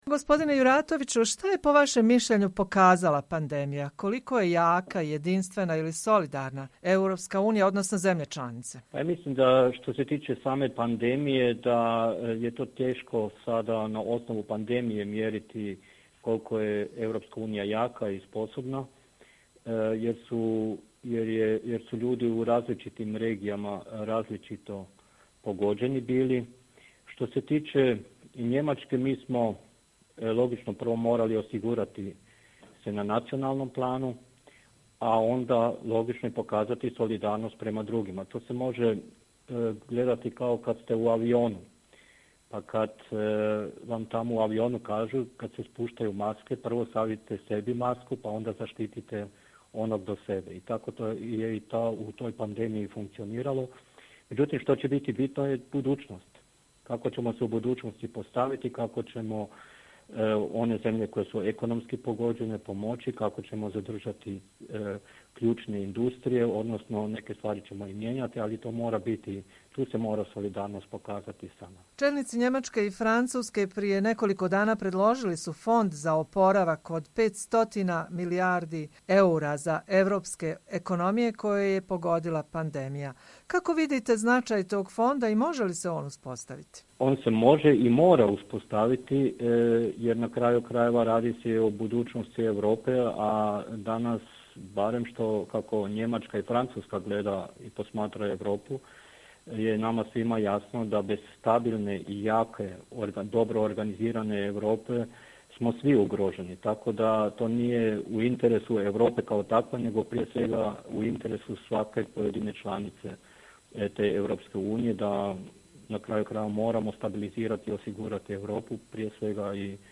Intervju sa Josipom Juratovićem